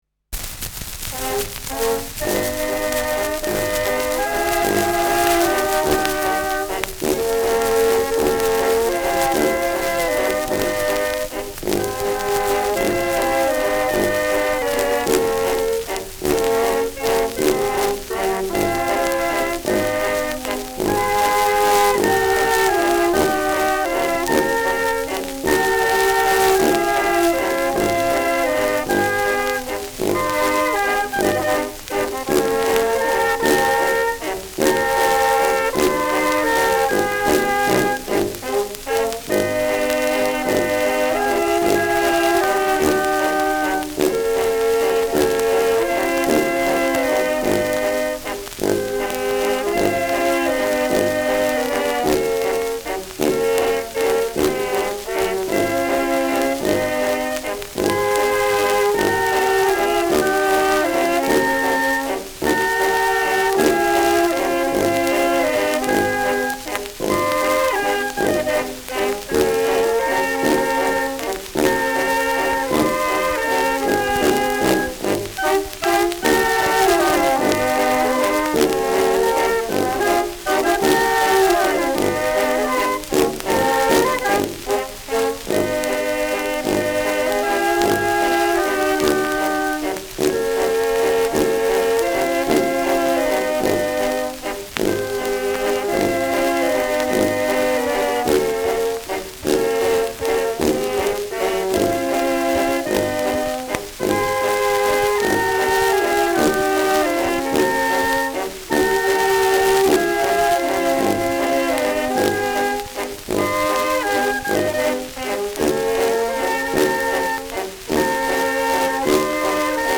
Santa Lucia : Walzer
Schellackplatte
präsentes Rauschen : präsentes Knistern : abgespielt : leiert : gelegentliches Knacken : gelegentliches „Schnarren“
Alpenländer Bauernkapelle (Interpretation)